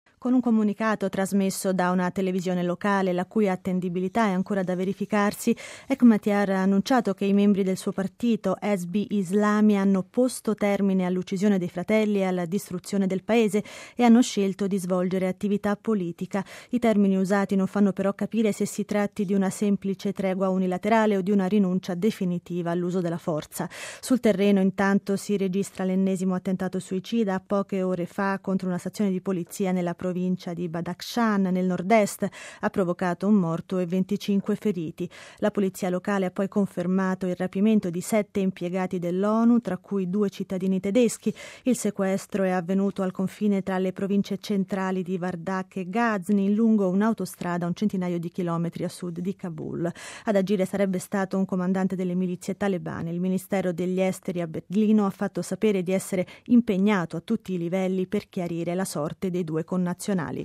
Uno dei più potenti 'signori della guerra', il leader Hekmatyar, ha annunciato un cessate-il-fuoco con il governo del presidente Karzai, decretando così la fine dell'insurrezione e il ritorno alla normale vita politica nazionale. Ma sul campo non si arresta la violenza. Ci aggiorna in studio